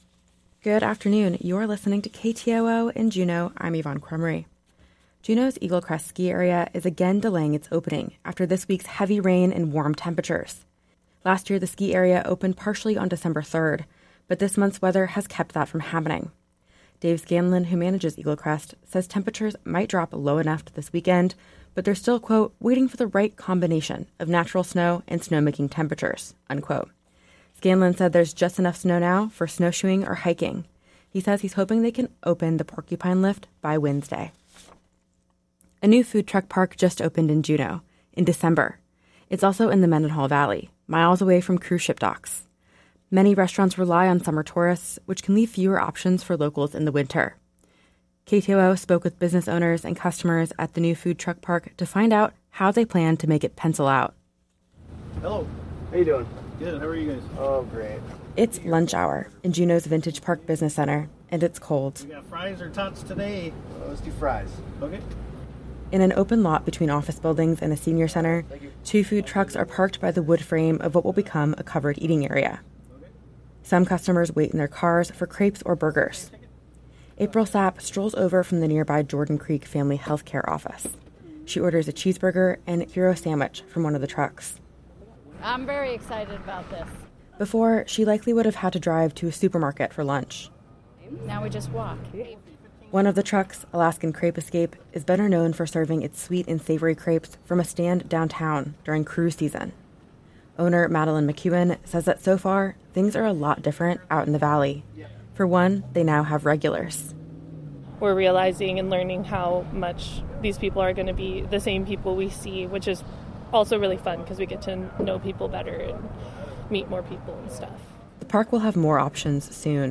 Newscast – Thursday, December. 14 2023